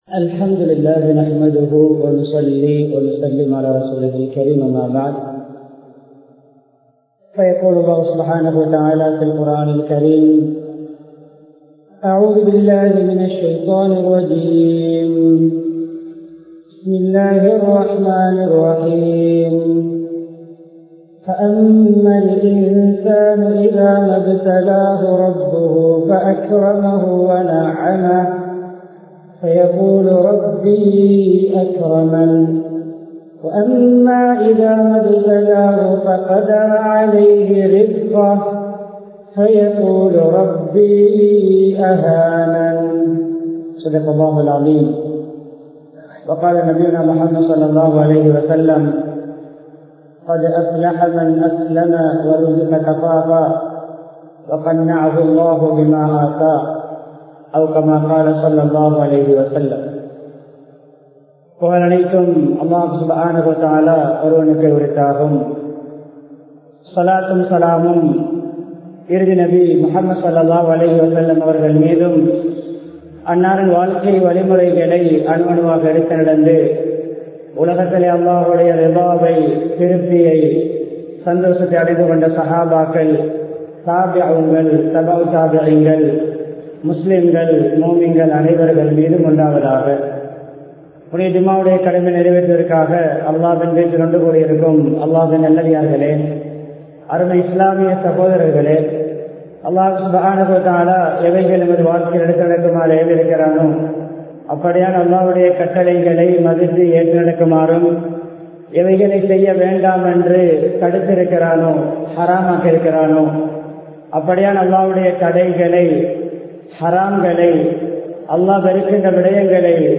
மனநிறைவு | Audio Bayans | All Ceylon Muslim Youth Community | Addalaichenai
Colombo 10, Maligawatttha, Grand Jumua Masjidh